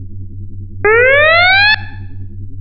Star_Trek_emergency_simulation.wav